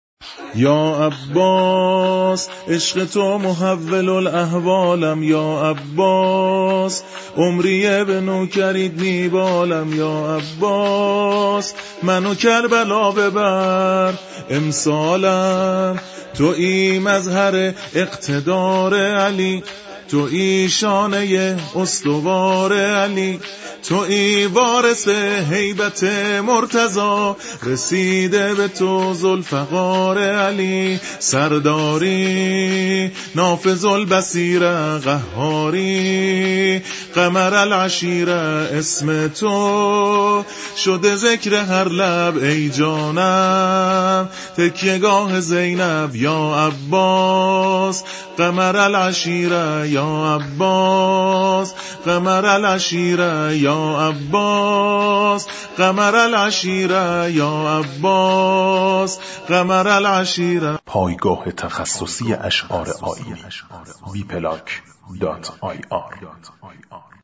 شور